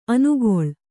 ♪ anugoḷ